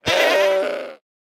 Minecraft Version Minecraft Version snapshot Latest Release | Latest Snapshot snapshot / assets / minecraft / sounds / mob / goat / death3.ogg Compare With Compare With Latest Release | Latest Snapshot